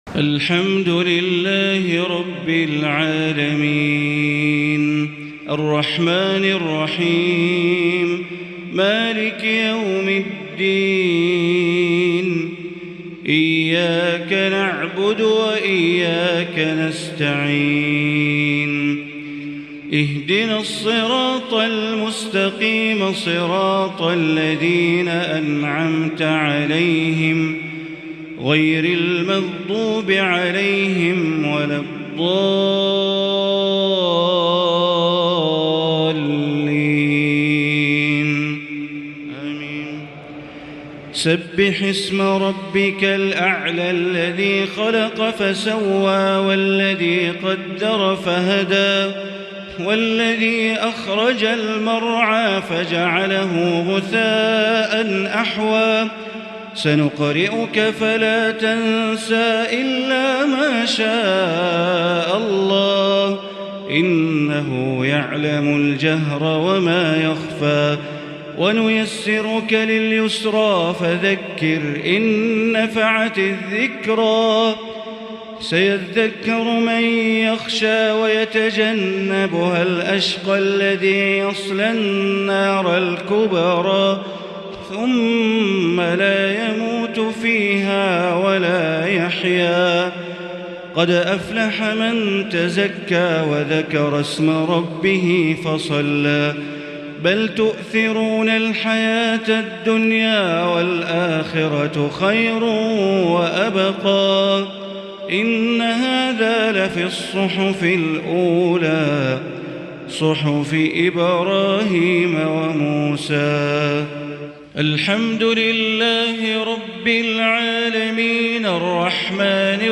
صلاة الجمعة 6 صفر 1444هـ سورتي الأعلى و الغاشية |Jumu’ah prayer from Surah Al-a’ala & Al-Ghashiya 1-9-2022 > 1444 🕋 > الفروض - تلاوات الحرمين